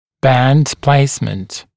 [bænd ‘pleɪsmənt][бэнд ‘плэйсмэнт]установка ортодонтического кольца (бандажного кольца)